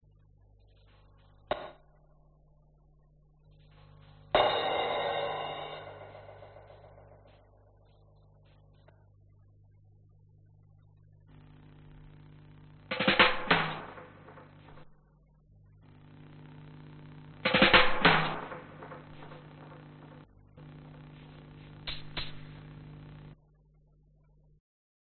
Tag: 英国委员会 合作 跨文化 嘻哈 跨文化 猕猴桃 音乐 新西兰 人在你身边 雷鬼